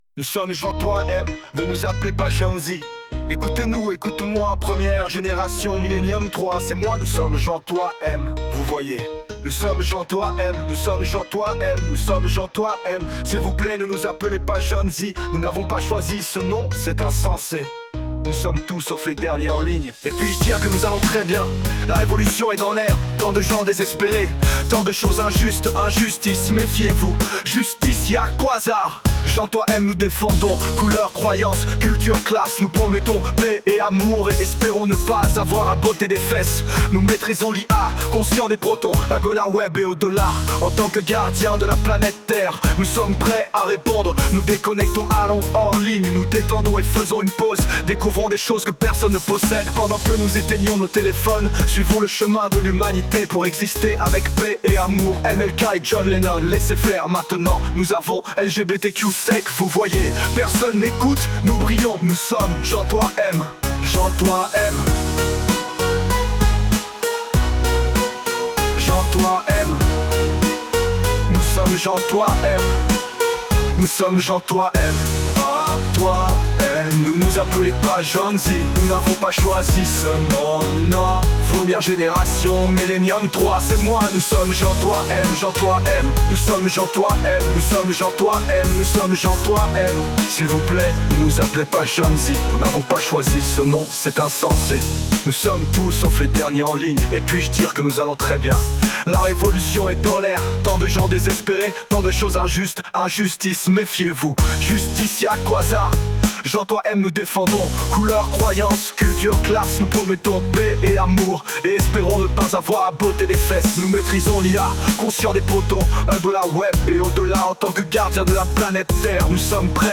Gen3M_France-rap-Reggae.mp3